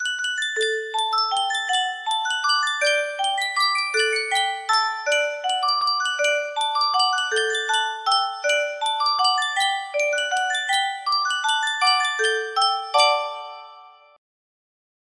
Sankyo Music Box - Brahms' Waltz AB music box melody
Full range 60